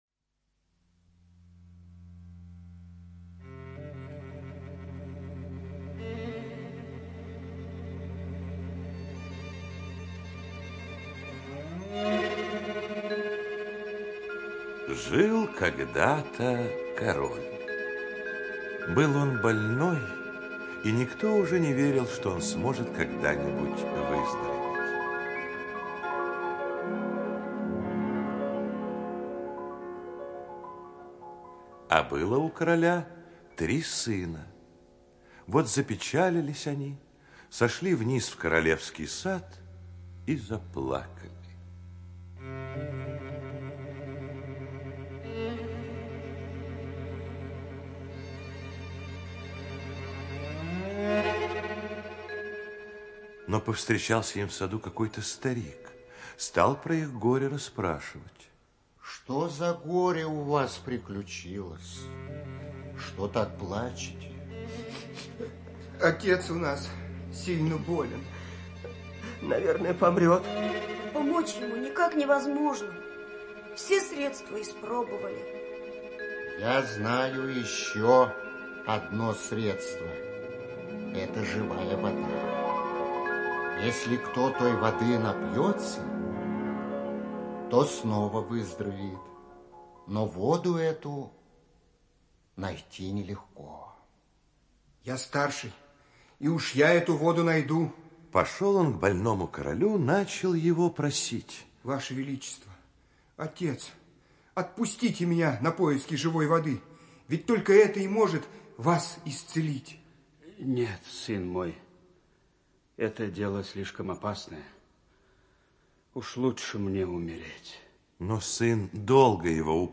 Живая вода - аудиосказка братьев Гримм. Сказка о трех братьях, которые отправились на поиски живой воды для больного отца.